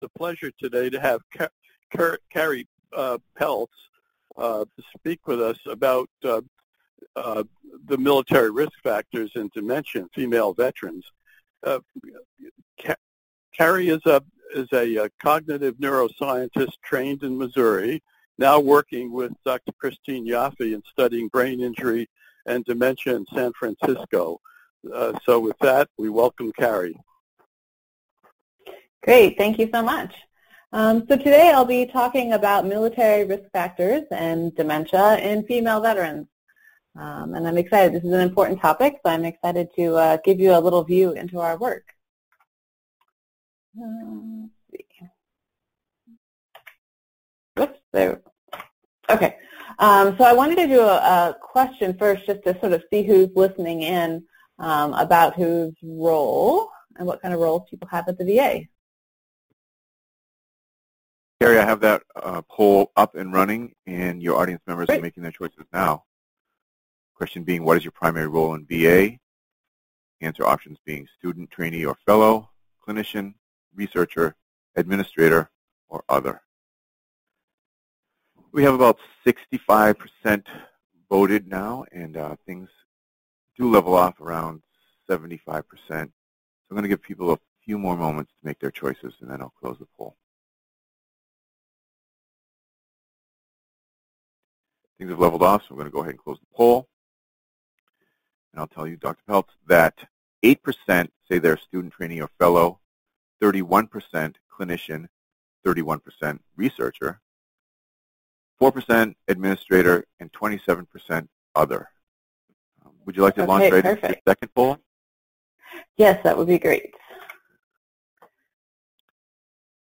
PhD Seminar date